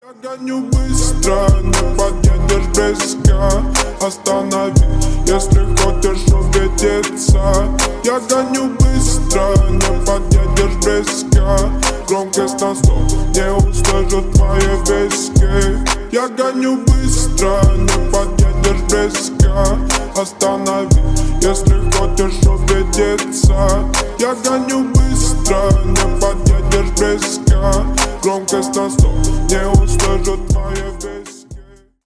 Ремикс